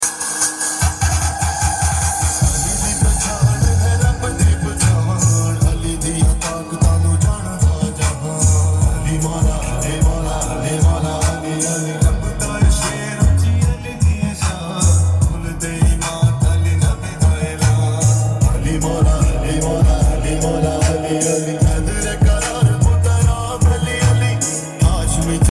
15 inches woofer speaker XBooM